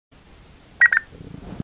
A další volitelnou možnost u automatických hlášení, pokud přeslechneme a nepotvrdíme nastavené hlášení, například "vezmi si léky", tak se každou minutu ozve
tento zvuk.